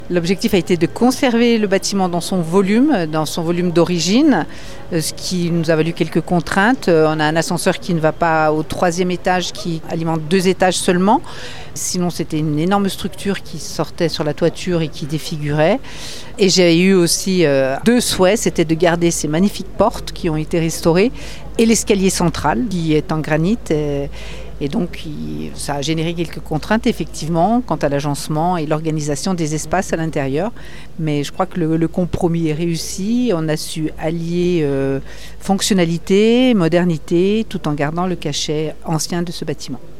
Catherine Jullien-Brèches, la maire de Megève :